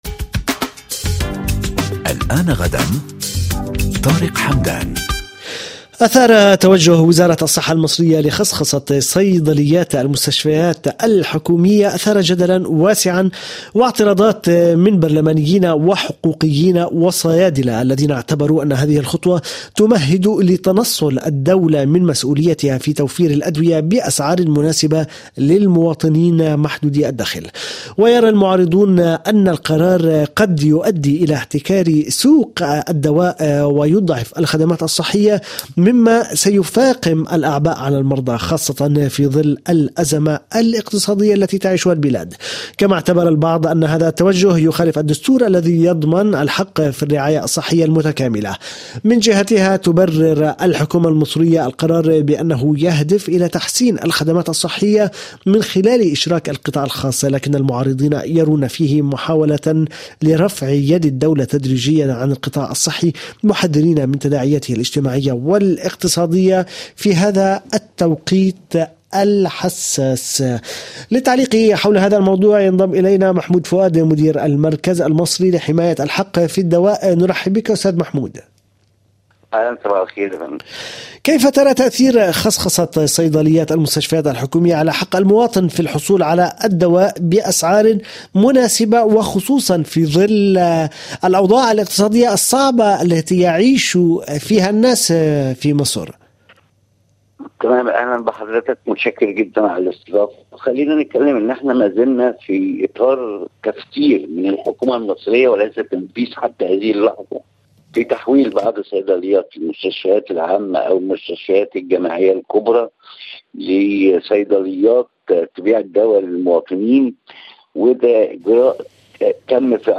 استمعوا إلى اللقاء الكامل من خلال الضغط على رمز التشغيل أعلى الصفحة.